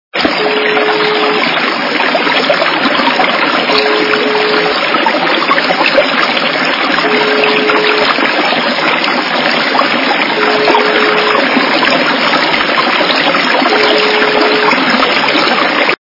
» Звуки » Природа животные » Журчание - воды
При прослушивании Журчание - воды качество понижено и присутствуют гудки.
Звук Журчание - воды